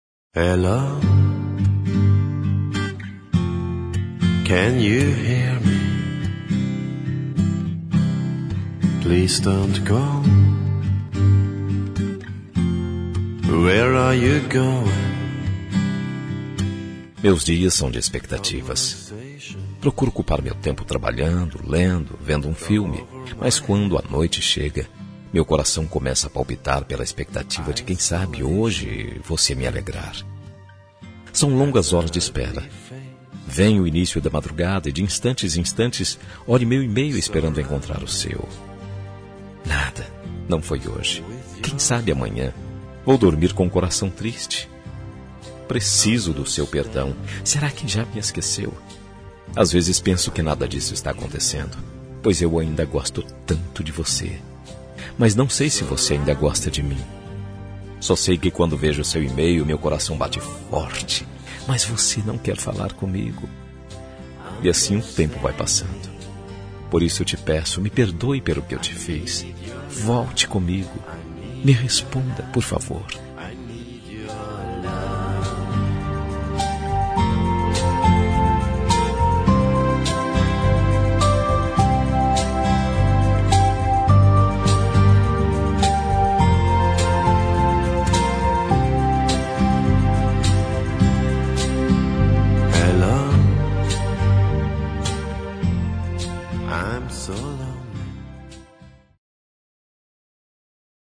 Telemensagem de Desculpas – Voz Masculina – Cód: 403